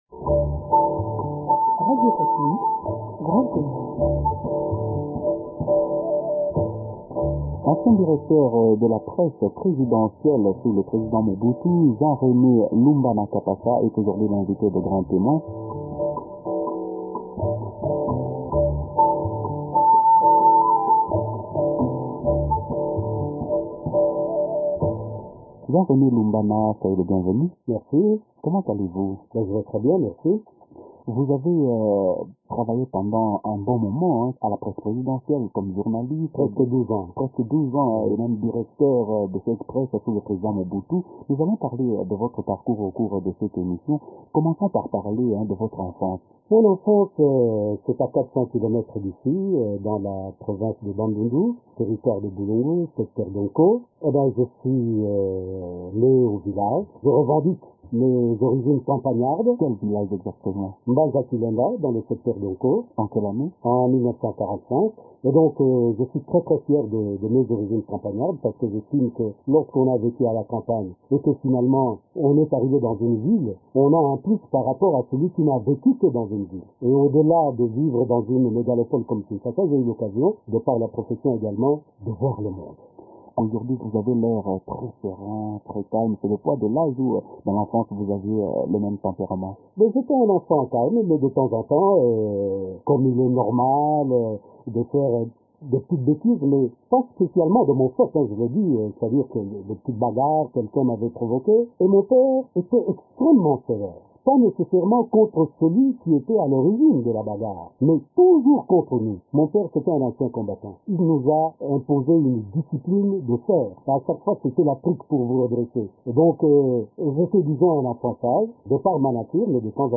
Dans son entretien